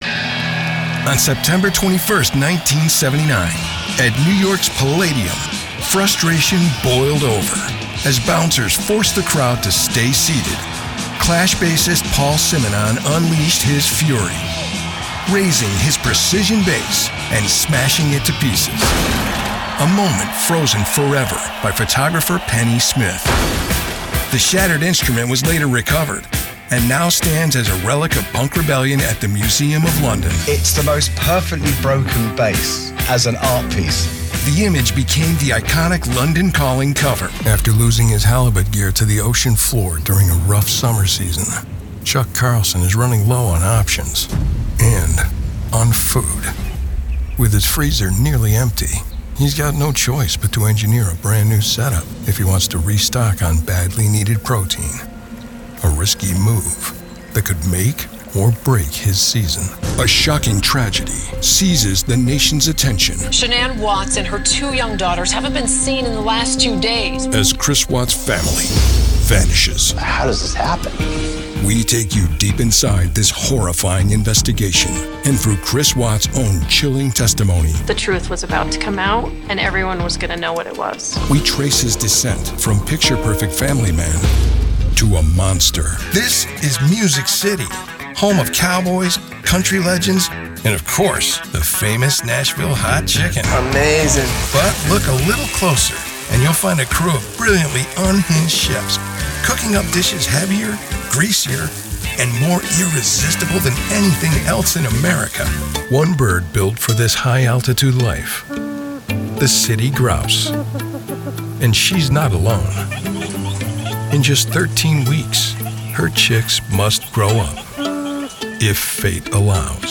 Fernsehshows
Selbstsichere Gen X Männerstimme für Ihr Projekt – der lässige, gesprächige Ton für Werbespots, Unternehmensvideos, Dokus oder Erklärvideos.
Mikrofone: Sennheiser MKH416 & Neuman TLM103
Akustisch behandelte professionelle Aufnahmekabine